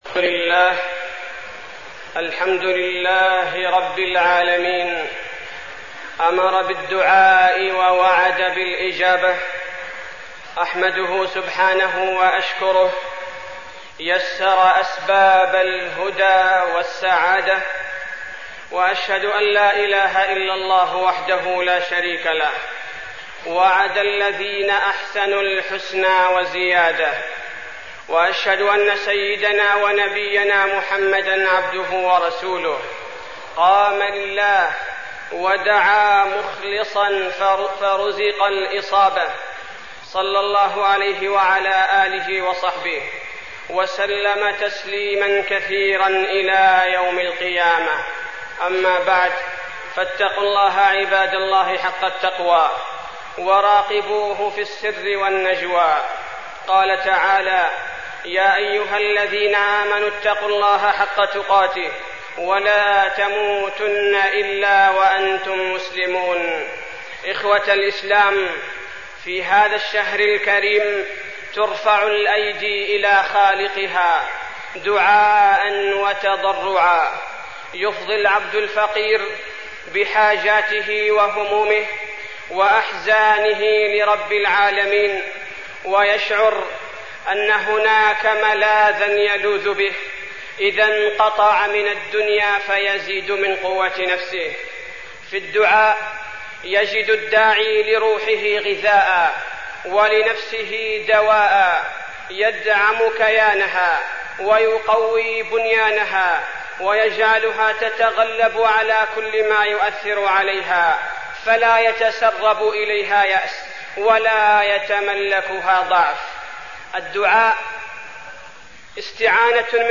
تاريخ النشر ١٥ رمضان ١٤١٧ هـ المكان: المسجد النبوي الشيخ: فضيلة الشيخ عبدالباري الثبيتي فضيلة الشيخ عبدالباري الثبيتي الدعاء وفضله